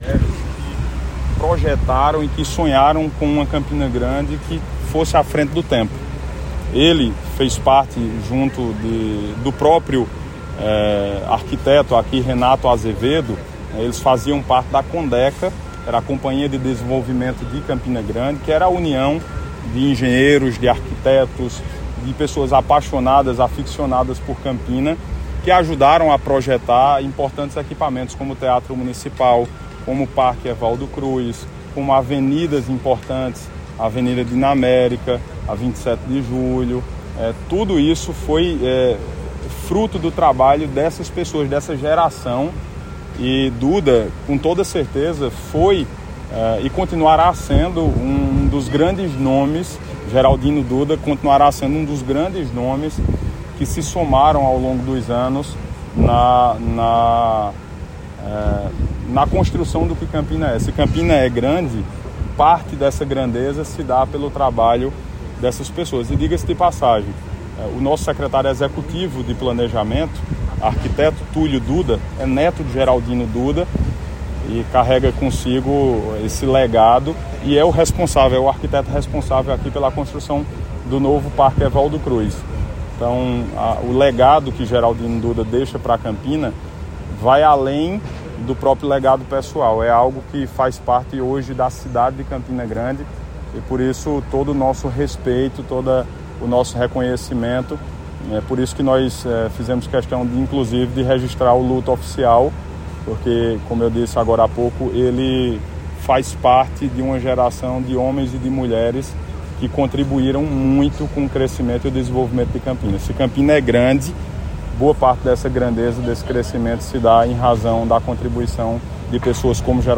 destacou o prefeito durante entrevista